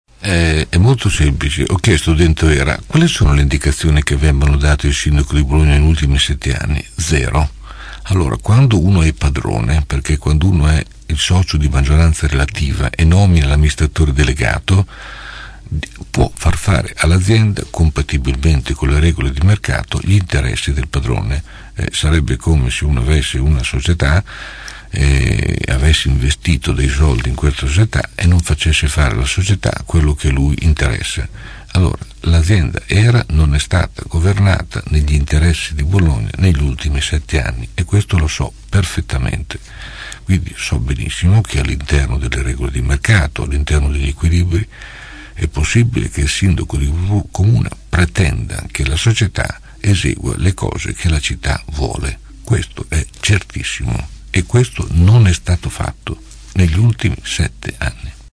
ospite questa mattina dei nostri studi.